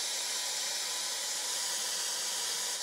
spraypaint-spray.ogg